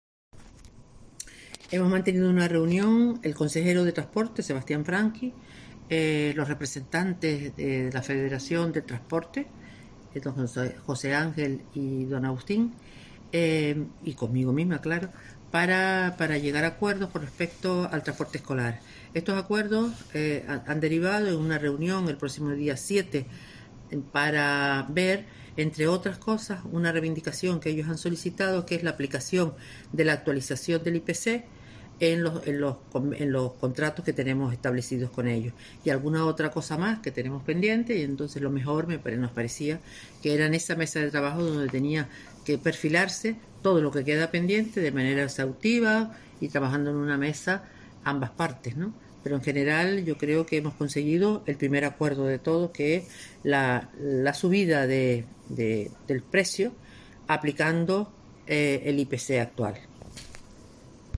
Manuela Armas, tras la reunión con los transportistas